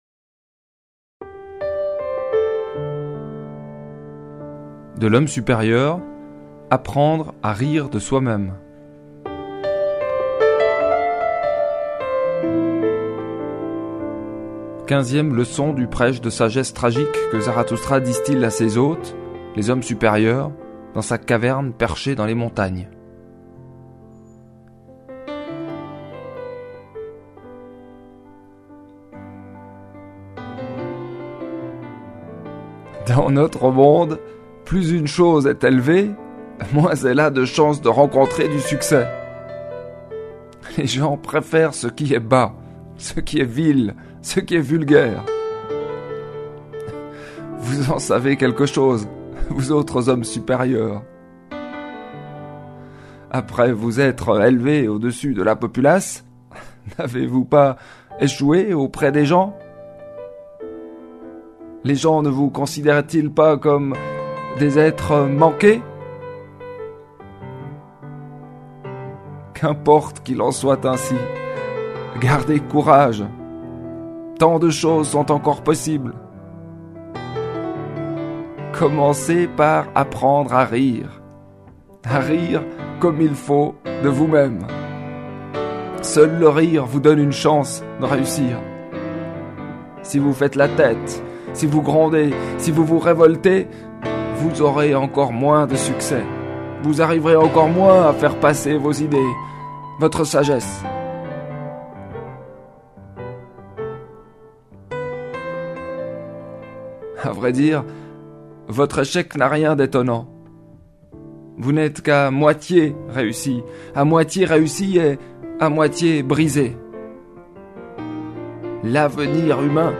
Musique : Keith Jarrett, Köln Concert, 1975.